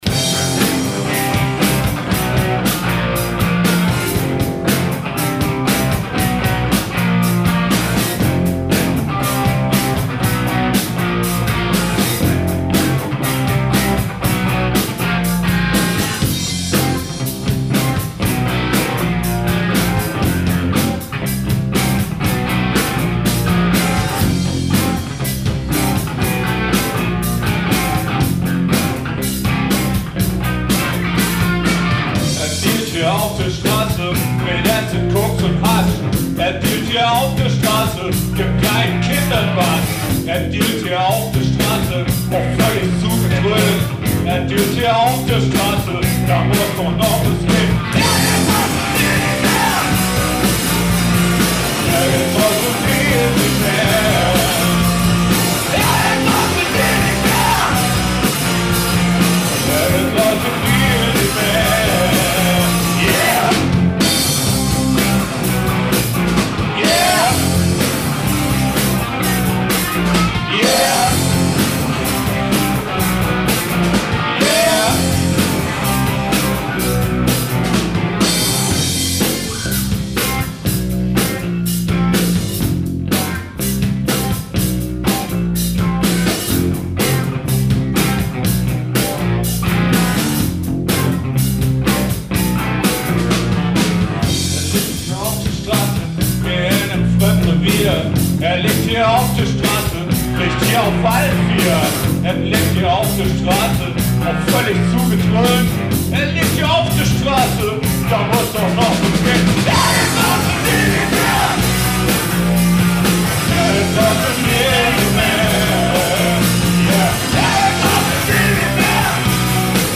Livemusik. Straighter melodischer Punkrock mit Tiefgang.
Drum, Bass, zwei E-Gitarren und Vocals.
Tempo: 118 bpm / Datum: 2012/13